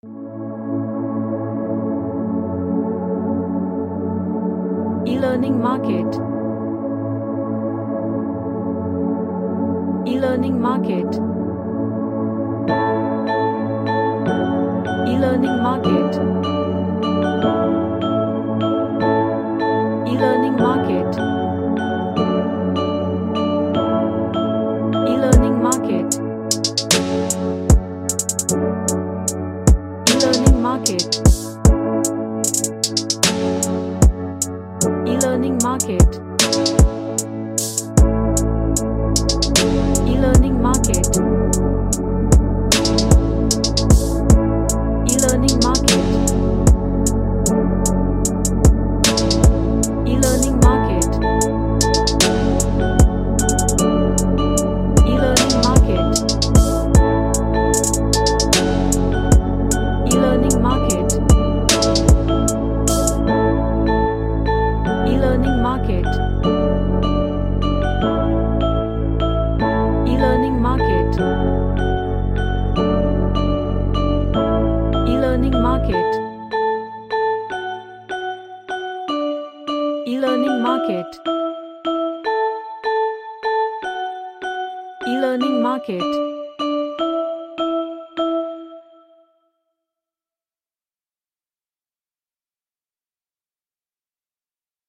A runaway lofi track
Relaxation / MeditationChill Out